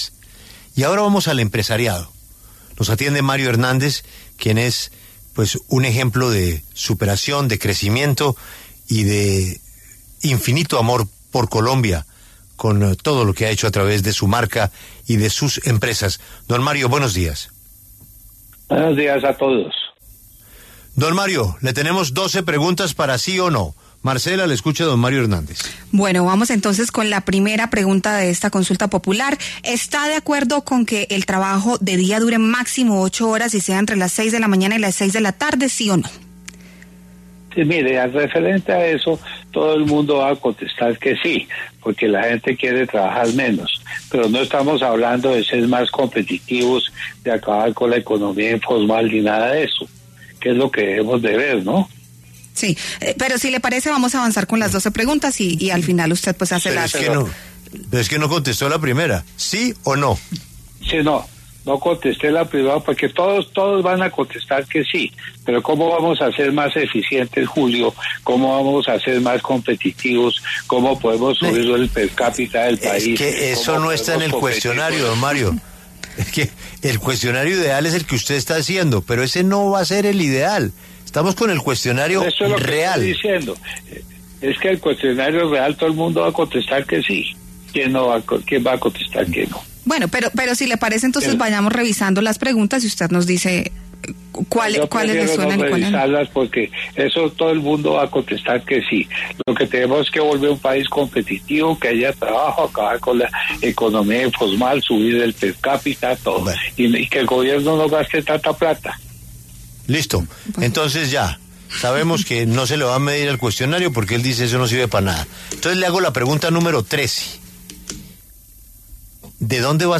El empresario Mario Hernández pasó por los micrófonos de La W donde criticó las preguntas que propuso el Gobierno Petro para la consulta popular, considerando que no resuelve los problemas estructurales del sector laboral.